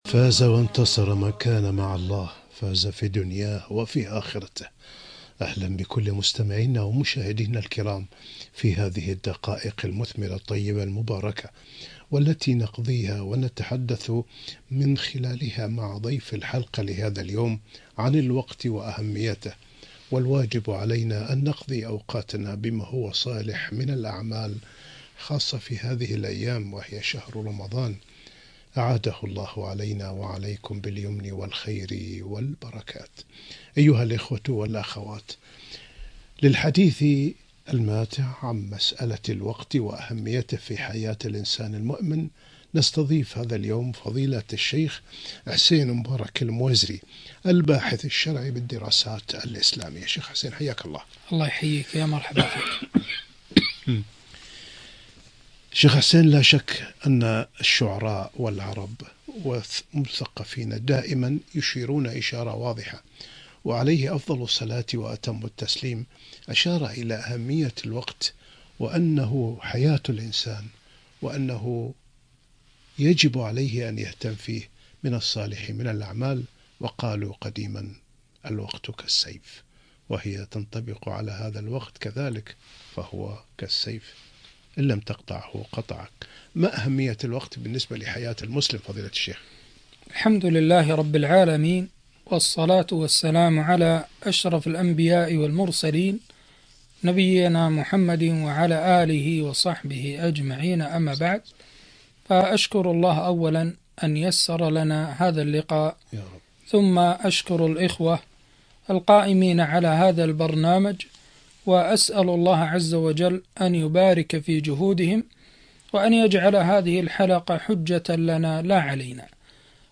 اغتنام الأوقات في الطاعات - لقاء إذاعي من برنامج واحة المستمعين